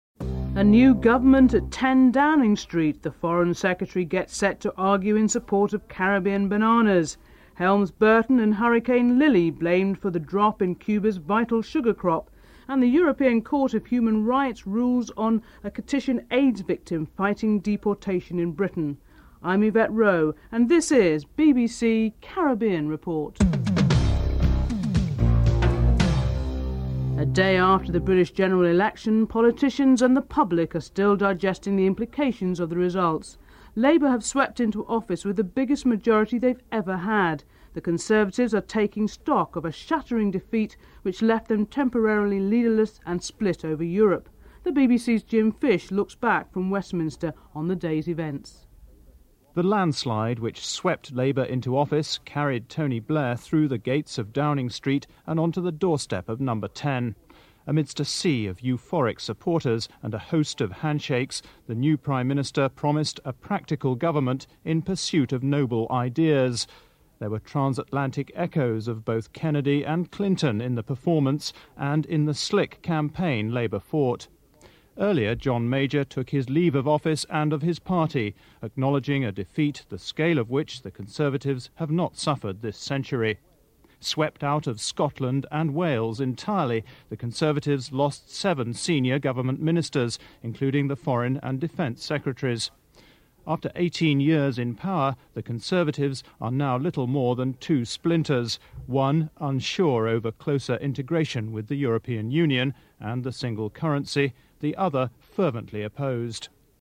1. Headlines (00:00-00:26)